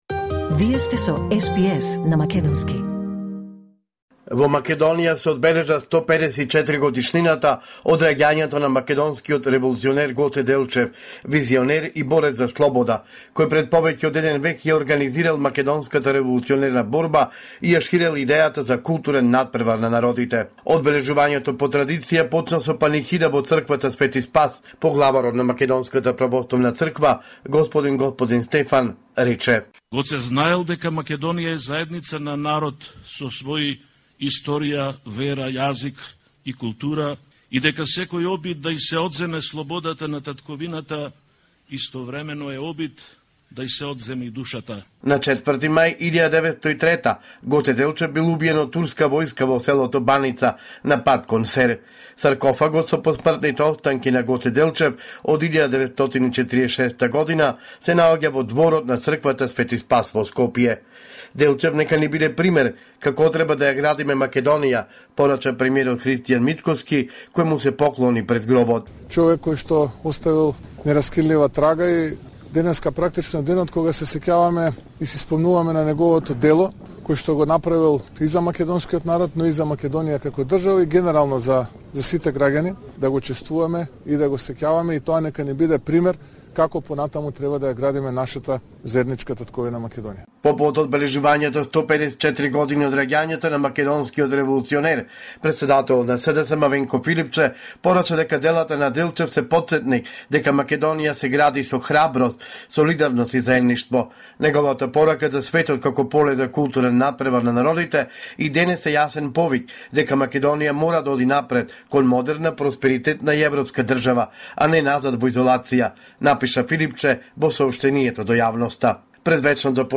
Извештај од Македонија 5 февруари 2026